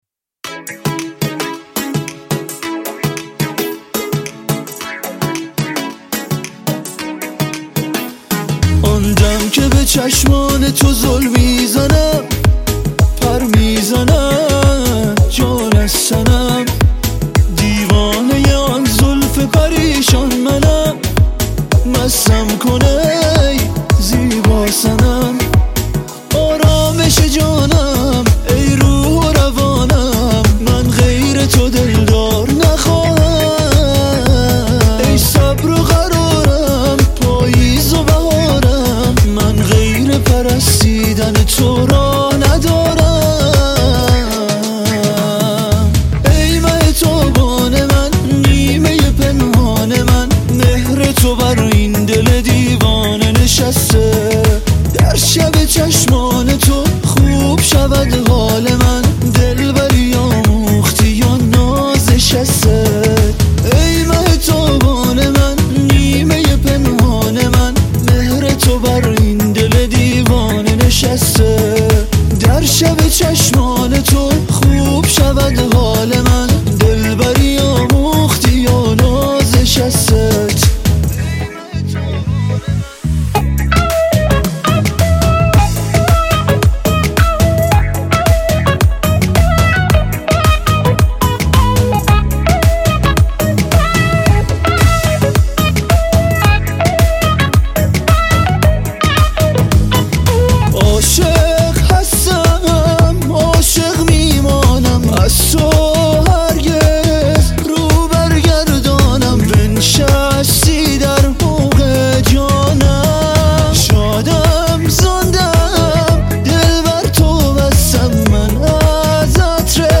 • پاپ
دسته : پاپ